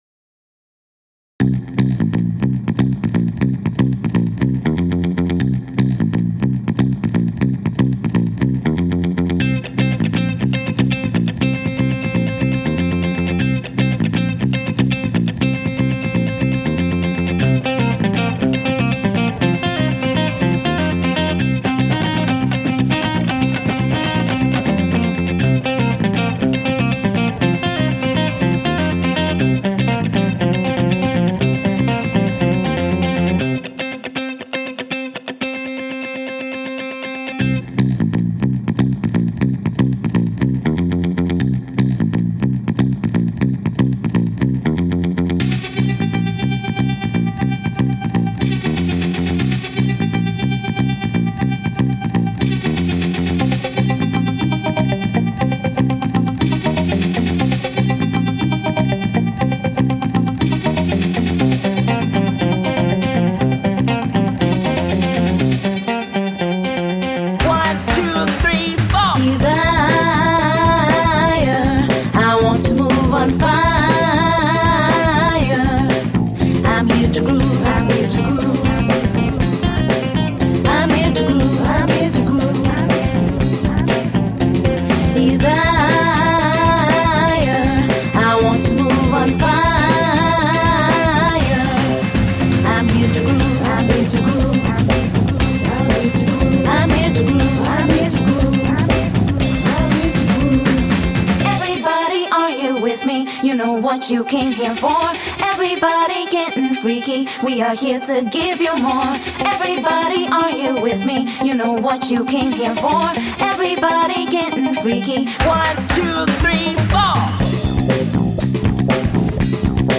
A new take on old funk.
Tagged as: Alt Rock, Funk, Rock, Electro Pop